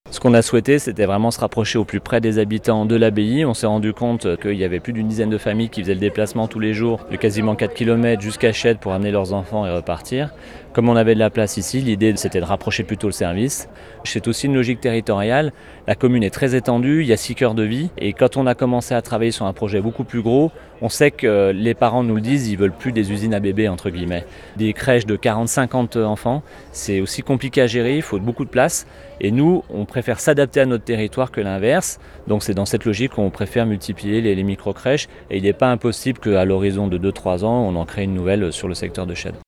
Raphaël Castéra est le maire de Passy.